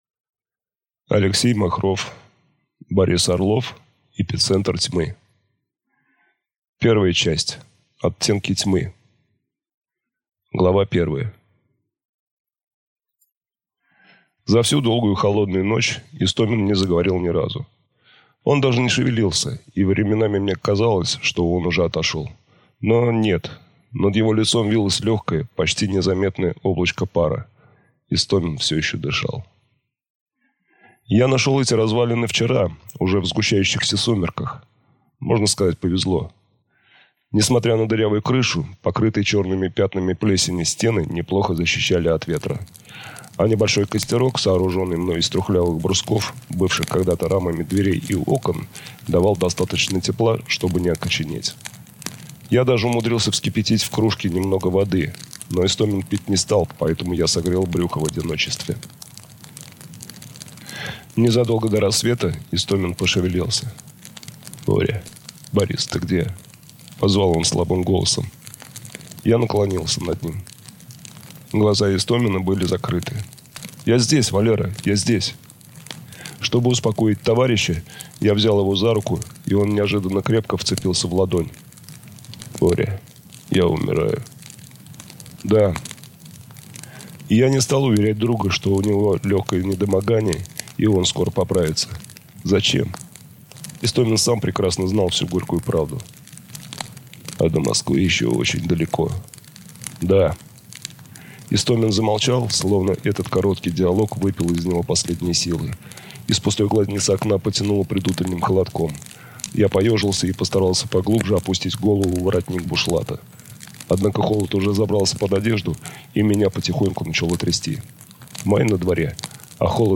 Аудиокнига Эпицентр Тьмы | Библиотека аудиокниг